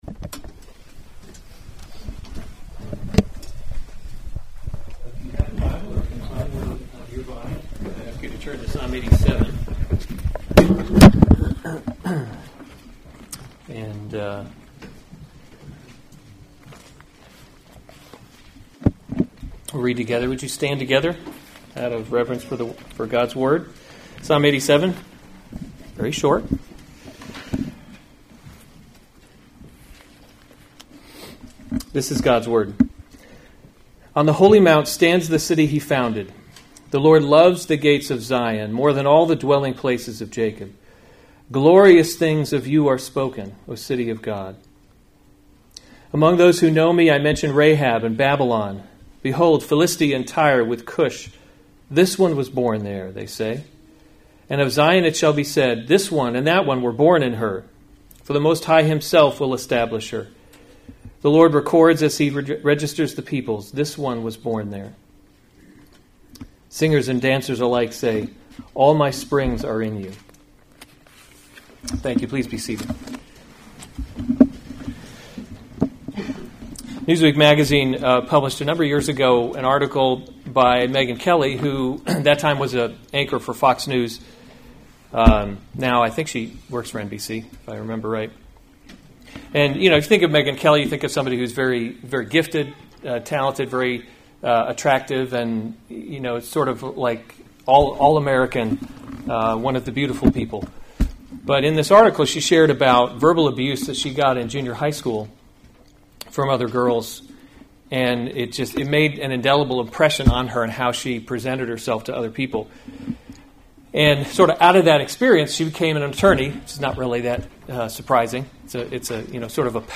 August 31, 2019 Psalms – Summer Series series Weekly Sunday Service Save/Download this sermon Psalm 87 Other sermons from Psalm Glorious Things of You Are Spoken A Psalm of the […]